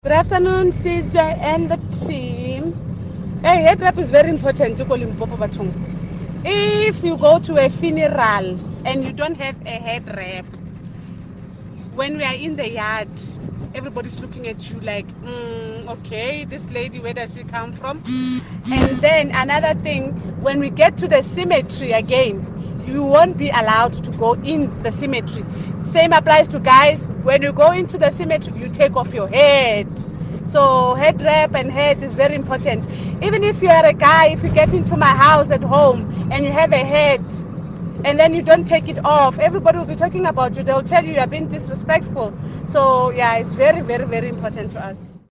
Listen to Kaya Drive listeners sharing what a headwrap means to them: